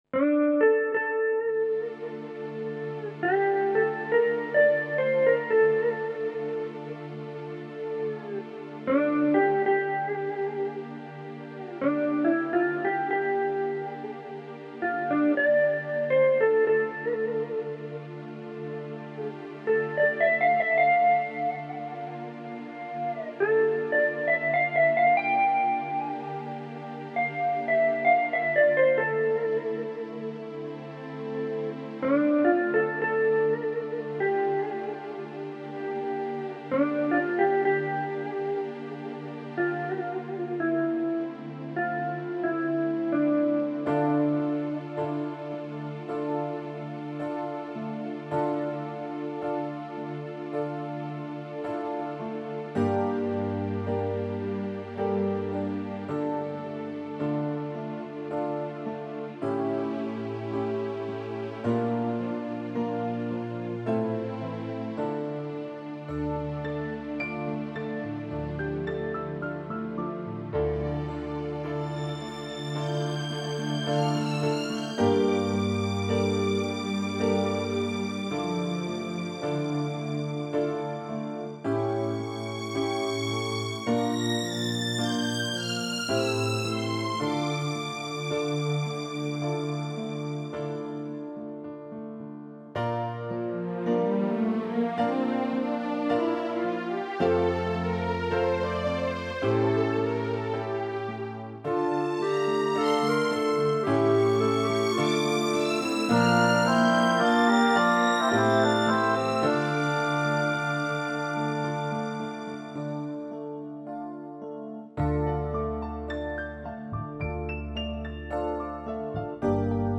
93-二胡曲-燕子-伴奏.mp3